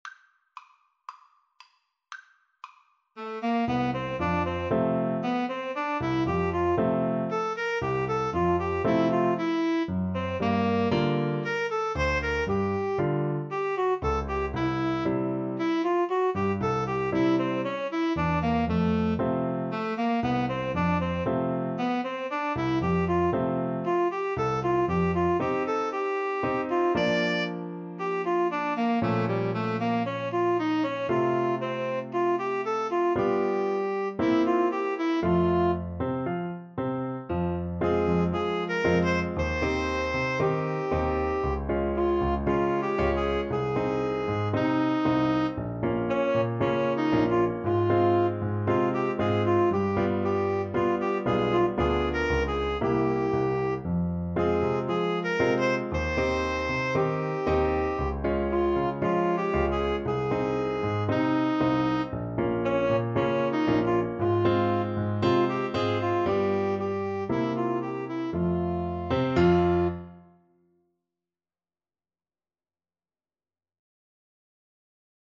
2/4 (View more 2/4 Music)